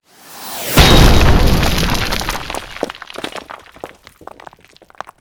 rocketgroundout.wav